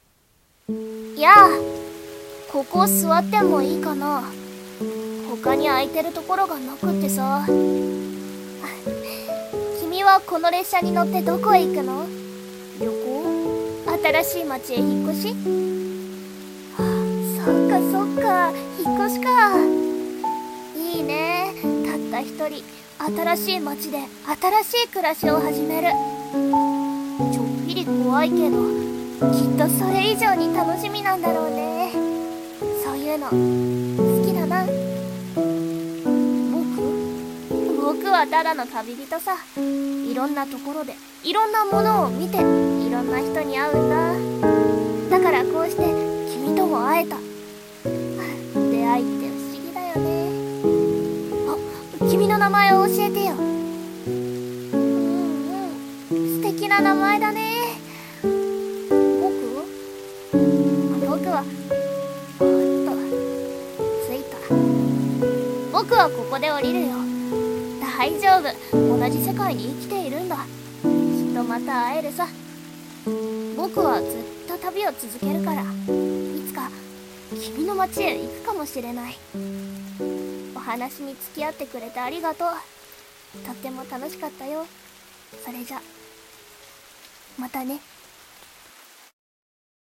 声劇「みしらぬネコ」